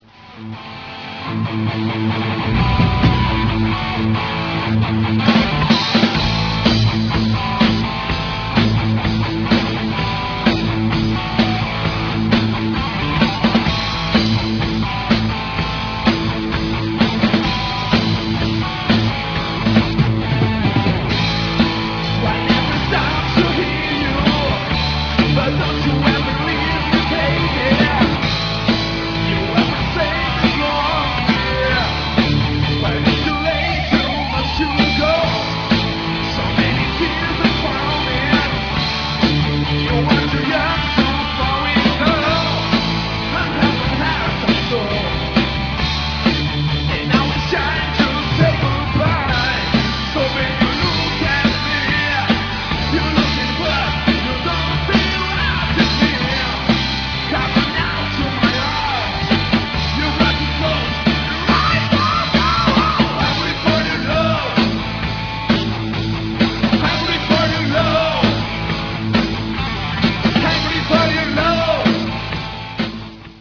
Third World Glam Rock Band